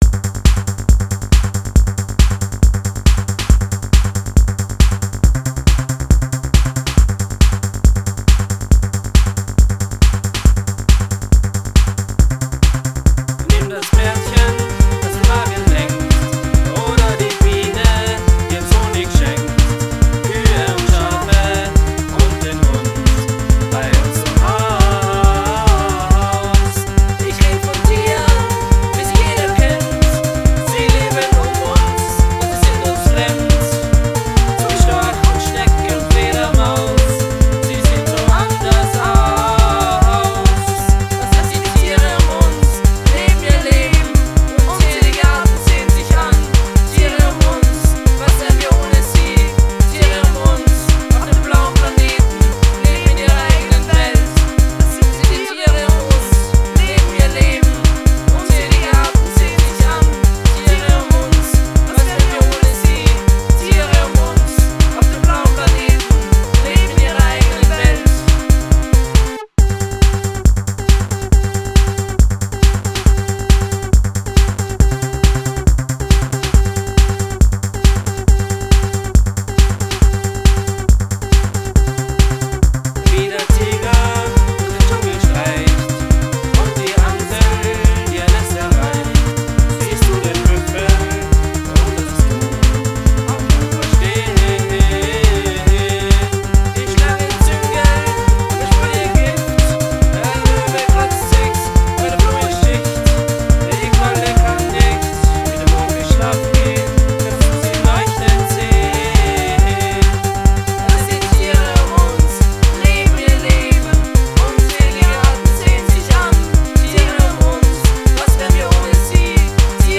ganz spezielle Disko-Reminiszenz